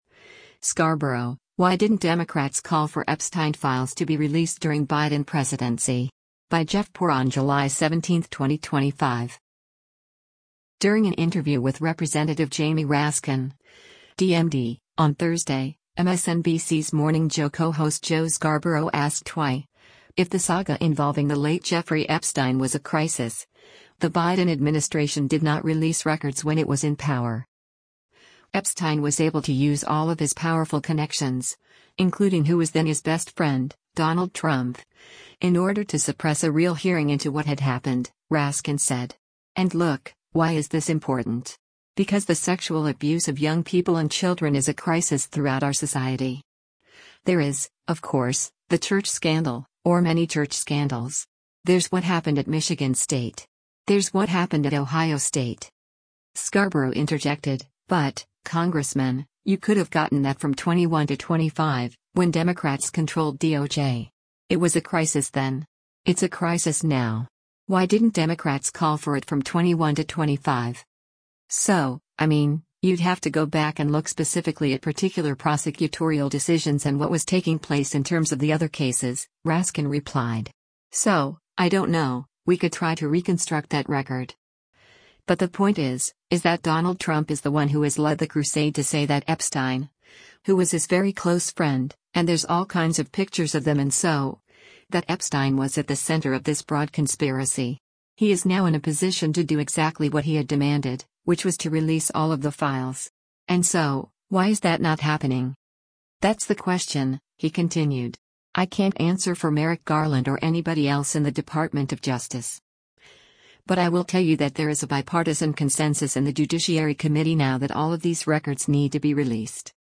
During an interview with Rep. Jamie Raskin (D-MD) on Thursday, MSNBC’s “Morning Joe” co-host Joe Scarborough asked why, if the saga involving the late Jeffrey Epstein was a “crisis,” the Biden administration did not release records when it was in power.